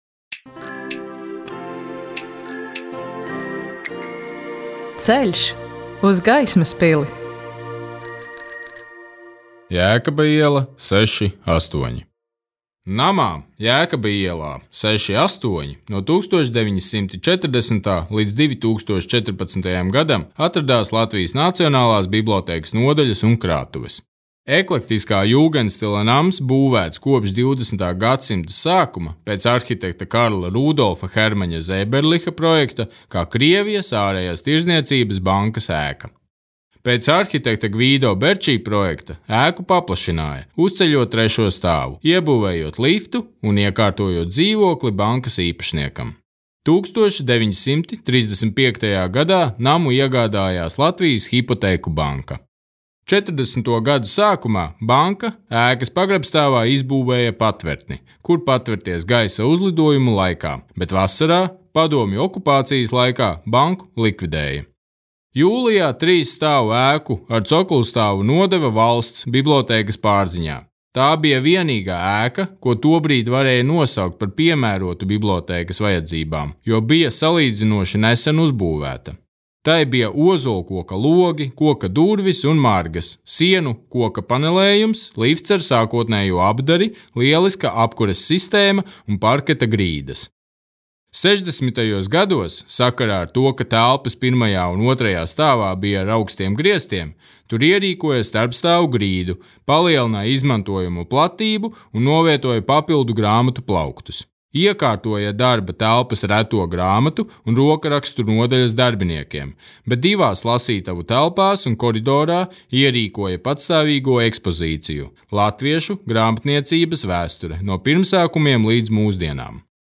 Ceļš uz Gaismas pili : audiogids